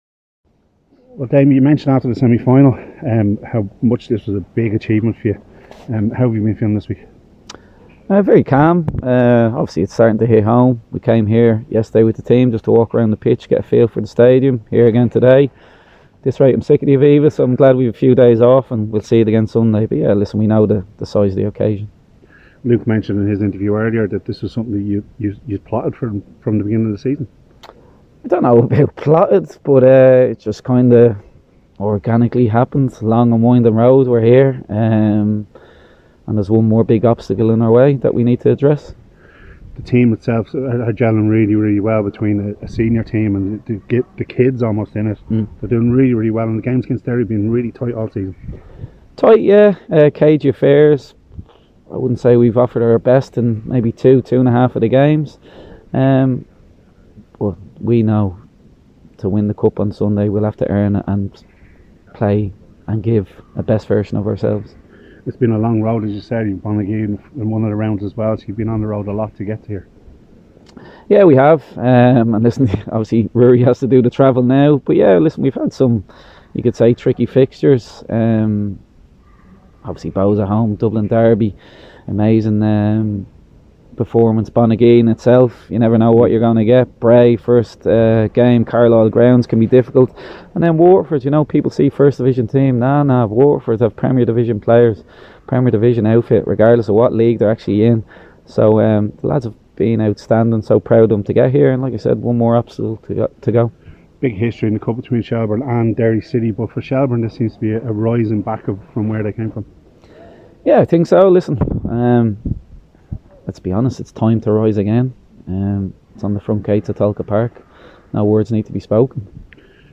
caught up with Shelbourne manager Damien Duff earlier to get his thoughts on Sunday's Final agains Derry at the Aviva Stadium.